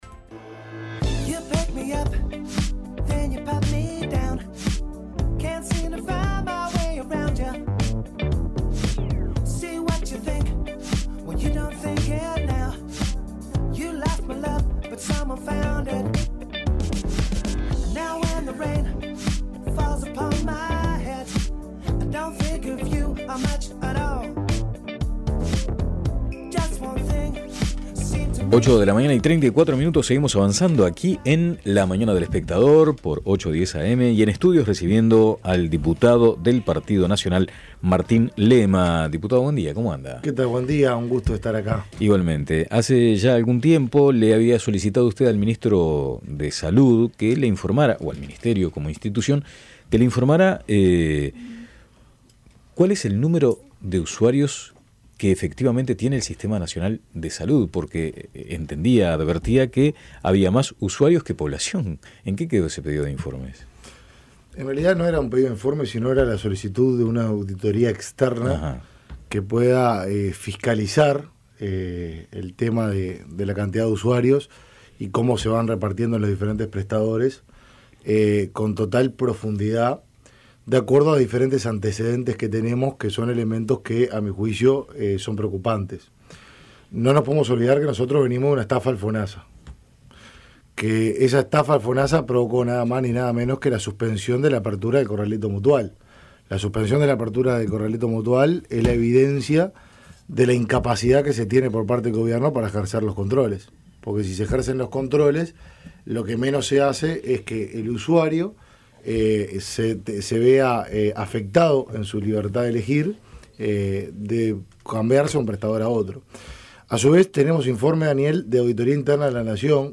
En relación a esto el legislador dijo a La Mañana de El Espectador que no hay que olvidarse de la estafa en FONASA, lo que generó la suspensión de la apertura del corralito mutual y a su vez recordó los informes de la Auditoría Interna de la Nación que evidencian "la incapacidad" que tiene el gobierno para fiscalizar.